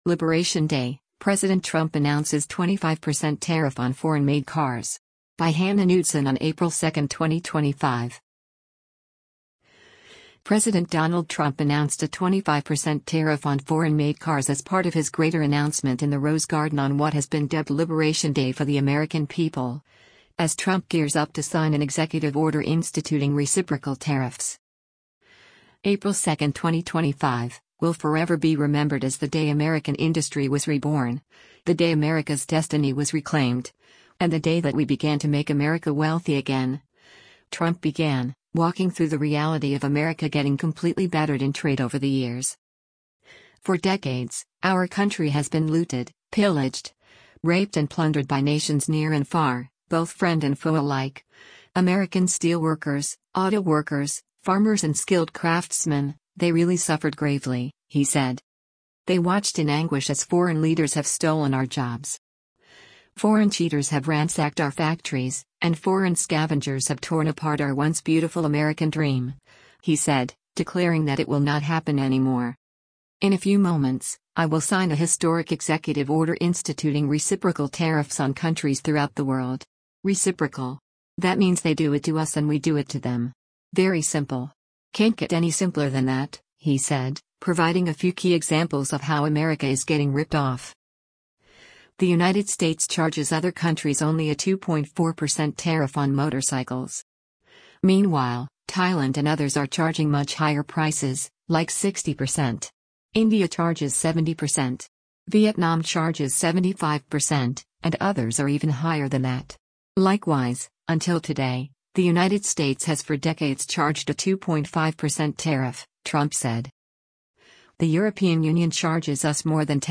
President Donald Trump announced a 25 percent tariff on foreign-made cars as part of his greater announcement in the Rose Garden on what has been dubbed “Liberation Day” for the American people, as Trump gears up to sign an executive order instituting reciprocal tariffs.